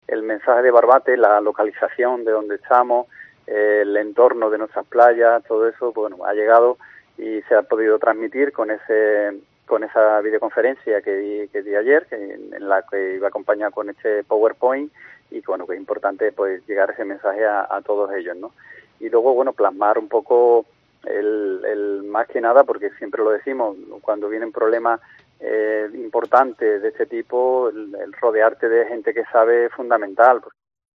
Miguel Molina, alcalde de Barbate